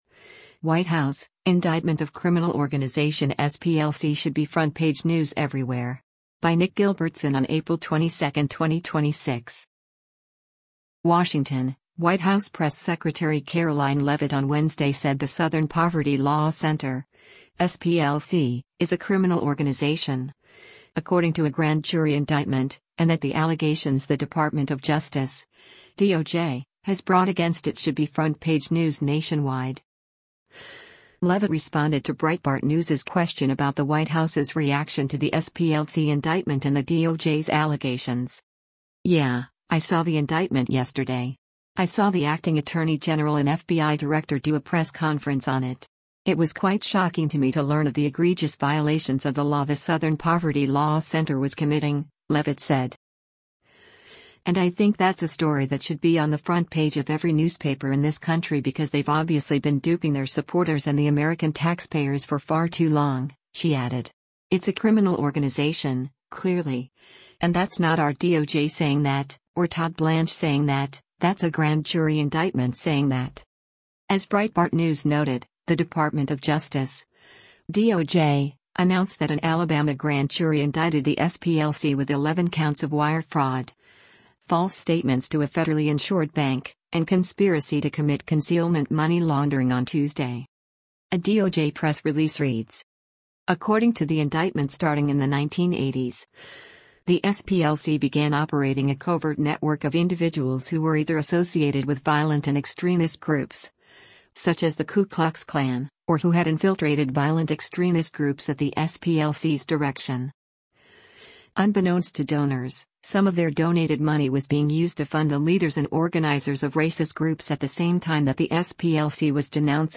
Leavitt responded to Breitbart News’s question about the White House’s reaction to the SPLC indictment and the DOJ’s allegations.